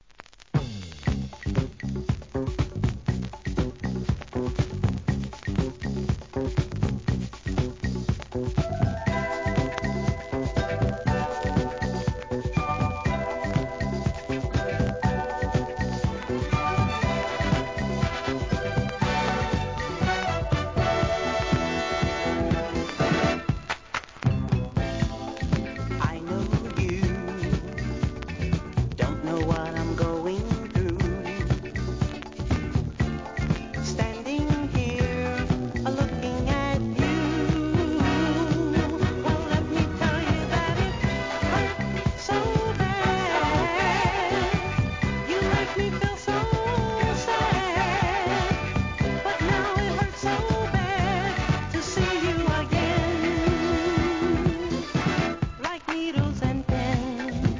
SOUL/FUNK/etc... 店舗 ただいま品切れ中です お気に入りに追加 1976年、フィリー産モダンソウル！